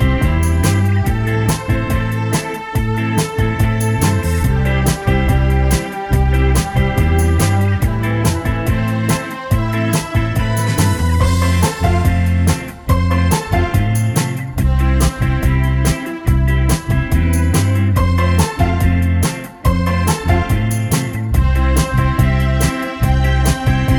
One Semitone Down Pop (2000s) 3:12 Buy £1.50